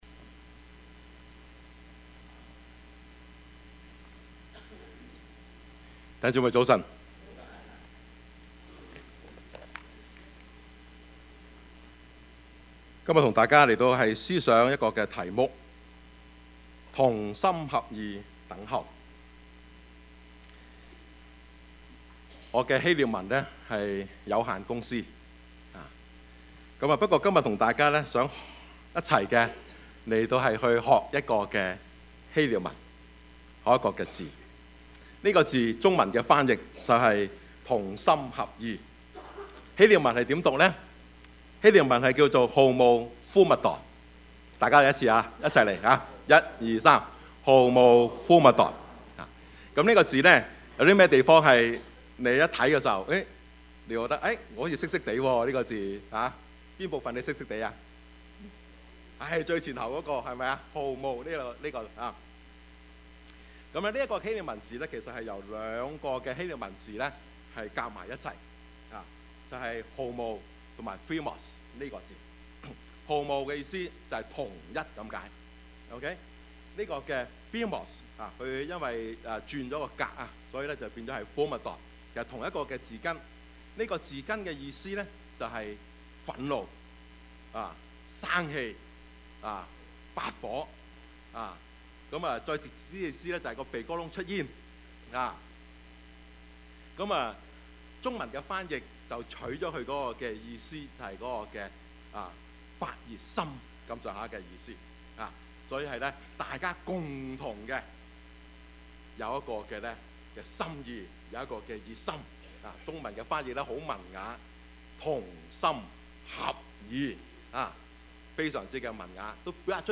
Service Type: 主日崇拜
Topics: 主日證道 « 三藩市(二) 同心合意分享 »